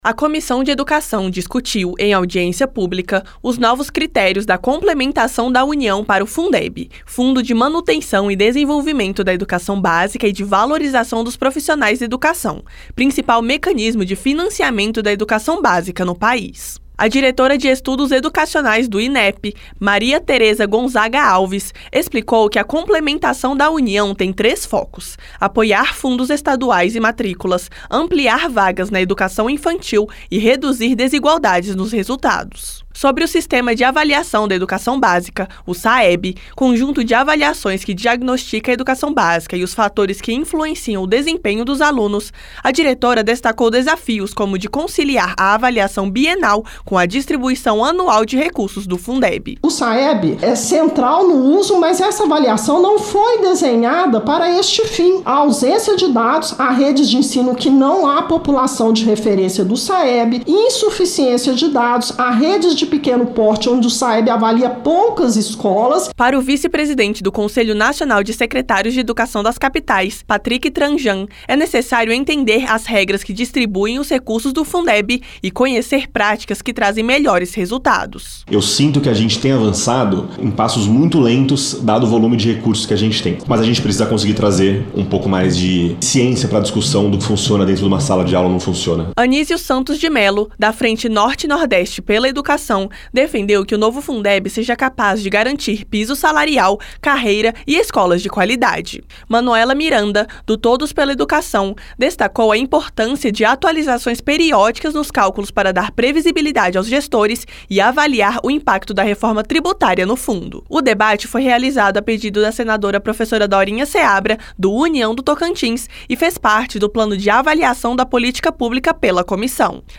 2. Notícias